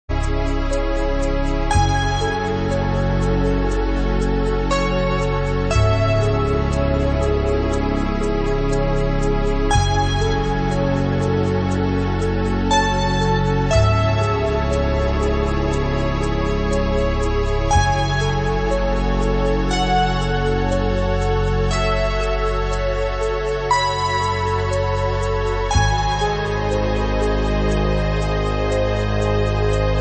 [Lo-Fi preview]